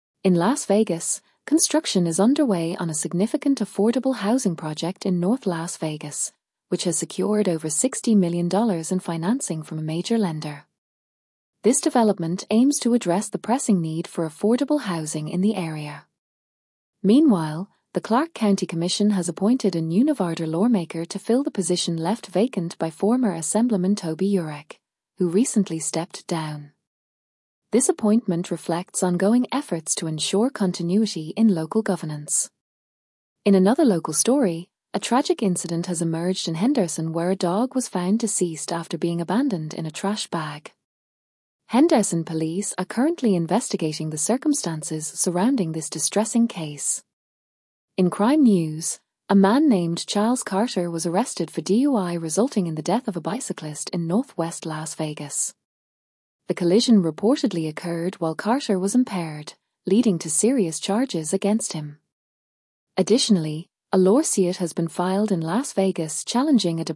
Regional News